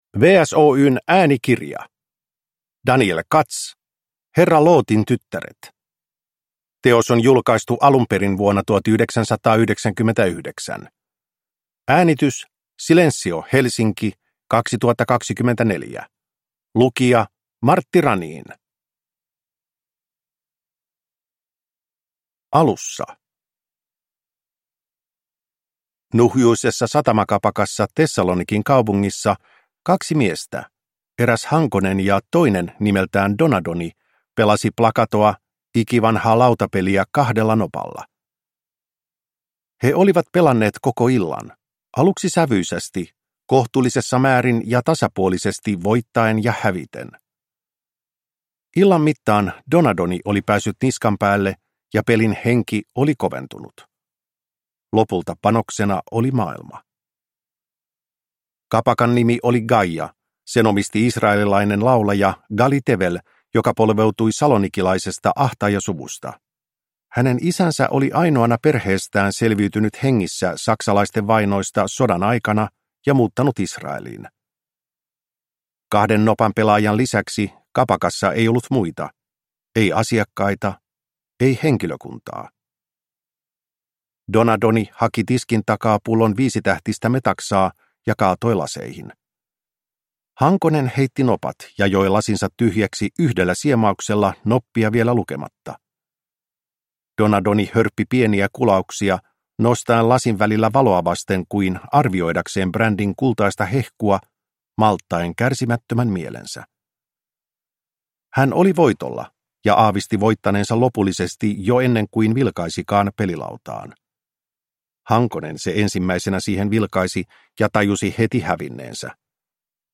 Herra Lootin tyttäret – Ljudbok